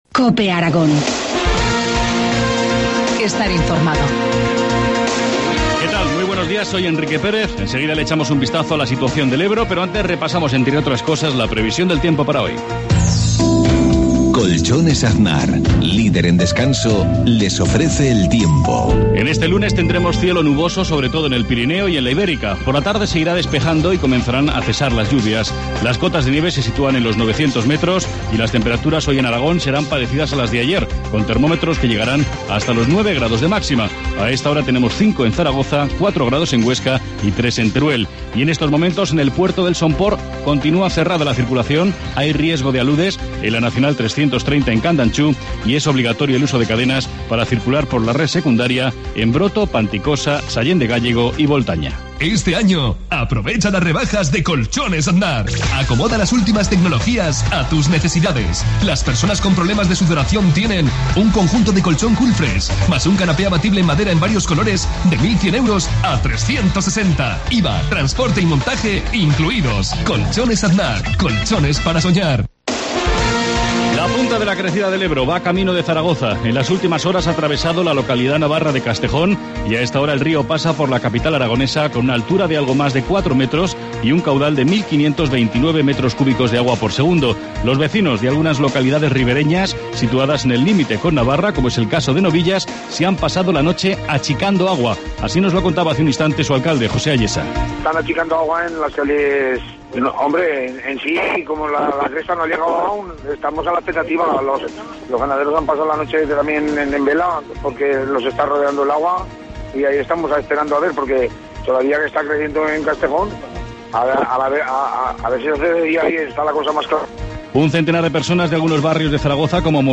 Informativo matinal, lunes 21 de enero, 7.53 horas